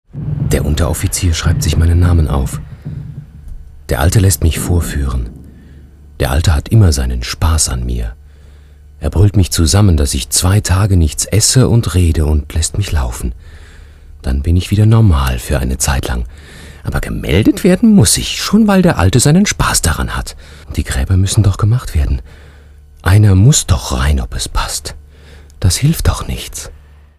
deutscher Sprecher. freiberuflicher Sprecher für ARD, ARTE, KIKA usw.
Sprechprobe: eLearning (Muttersprache):